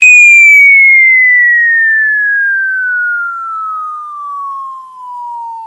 알림음 8_BombDroppingSound.mp3